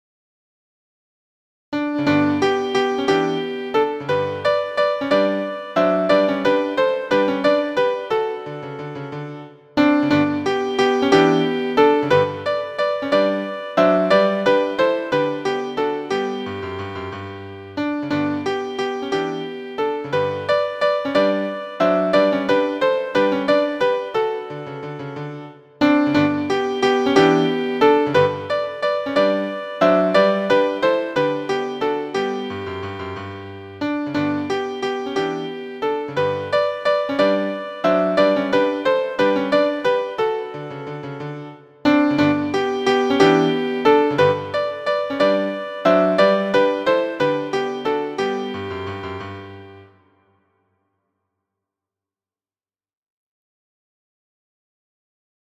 • Key: D Major
• Time: 6/8
• Form: ABCABc
• Musical Elements: notes: dotted quarter, quarter, eighth; rest: eighth; pickup beat, tied notes, repeat, multiple endings, dynamics: crescendo, decrescendo, mezzo-forte